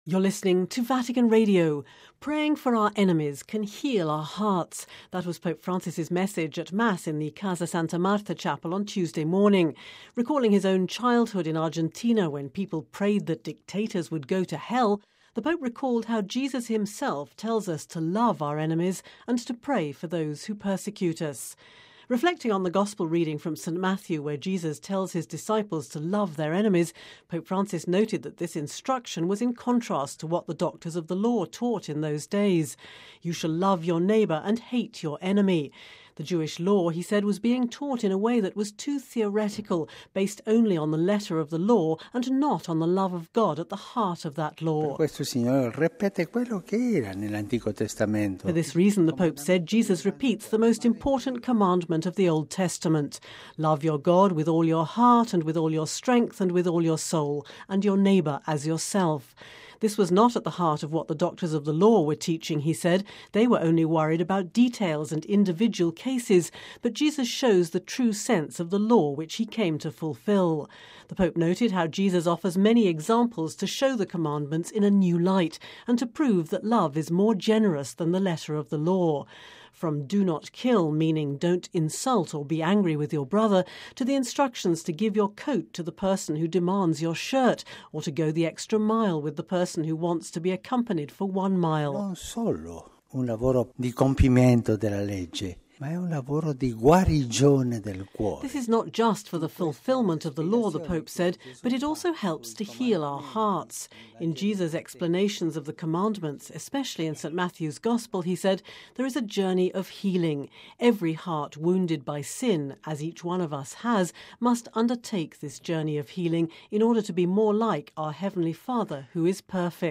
(Vatican Radio) Praying for our enemies can heal our hearts: that was Pope Francis’ message at Mass in the Casa Santa Marta chapel on Tuesday morning.